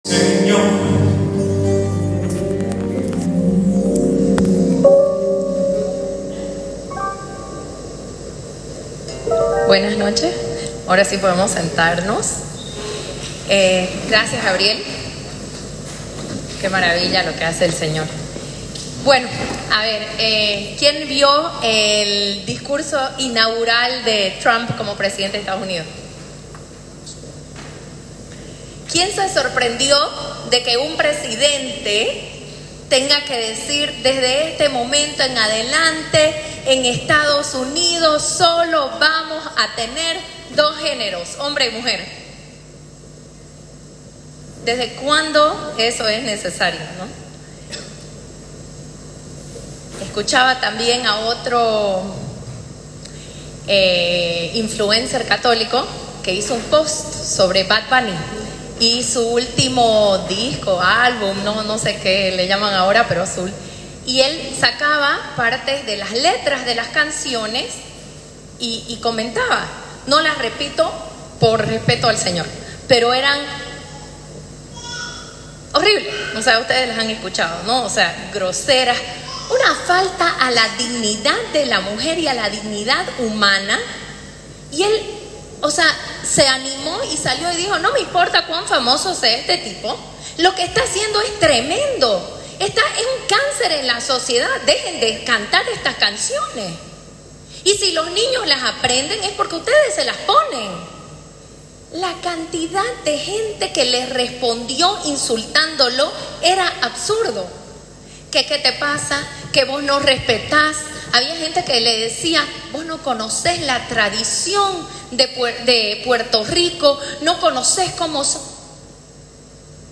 CICLO:Seminario de vida en el Espiritu Predica